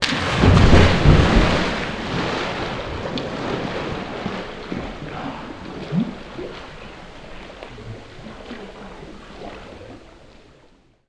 Water / Splashes
SPLASH_Deep_04_mono.wav